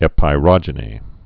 (ĕpī-rŏjə-nē)